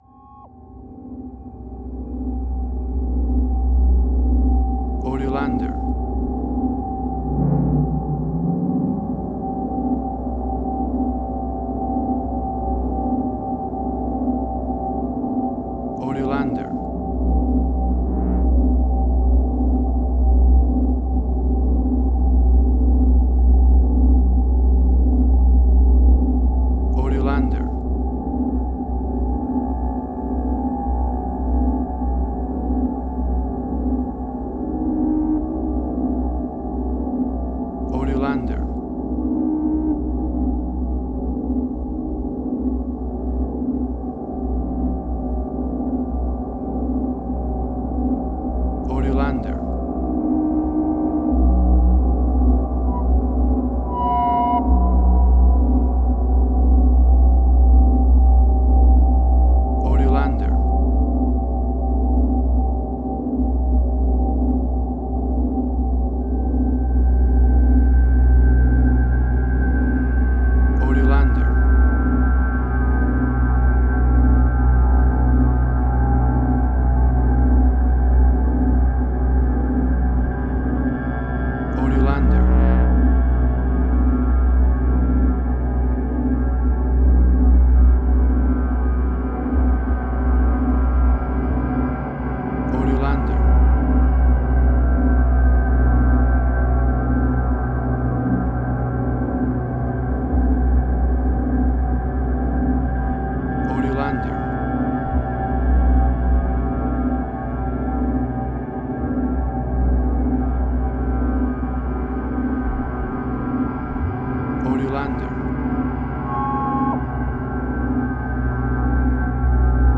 Post-Electronic.
Tempo (BPM): 116